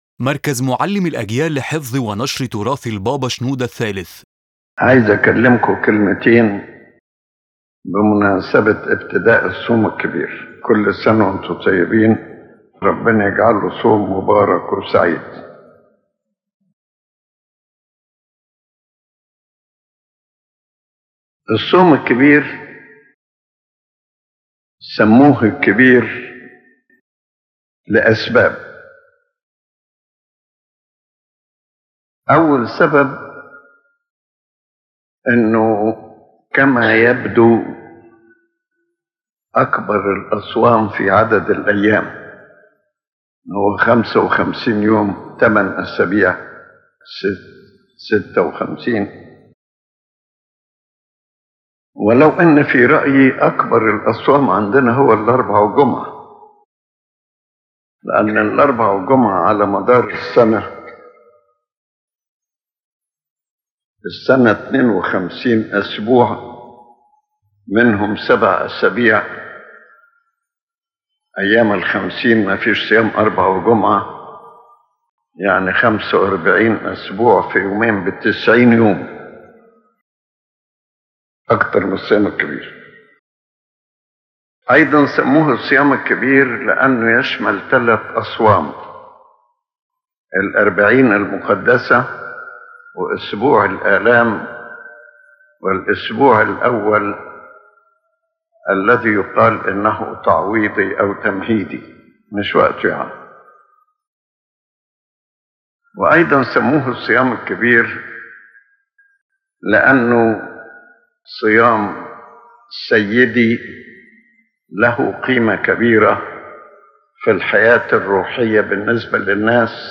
His Holiness Pope Shenouda begins by greeting the faithful for the start of the Great Lent, explaining that it is called “Great” because it is the longest fast, and also because it holds great spiritual value, including the Holy Forty Days and Holy Week.